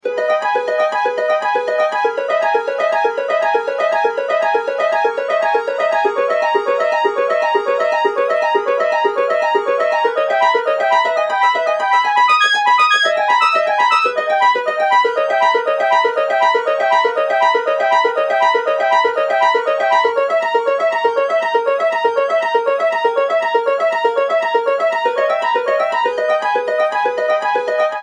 Sonificação das alterações hormonais durante o ciclo sexual ...
Fonte – Adaptado de Fox 2011 Mapeamento – alterações hormonais durante o ciclo sexual feminino para frequência Programa: Twotones FSH – harpa /1 oitava / track tempo: 8 x LH – trompete /1 oitava / track tempo: 8 x Estrogénios – violino /1 oitava / track tempo: 2 x Progesterona – piano /1 oitava / track tempo: 1 x Alteracoes-hormonais-no-ciclo-sexual-feminino.mp3 Quote